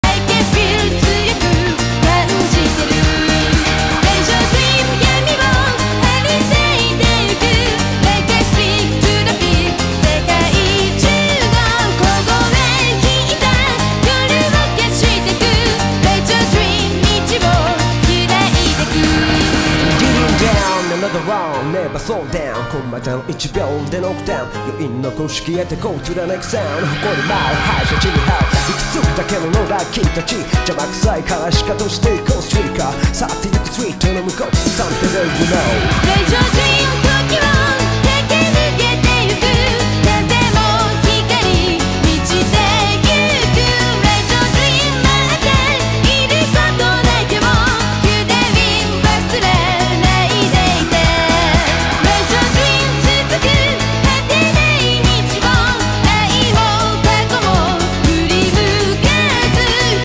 Eurobeat + Snow + 86 sound effects free download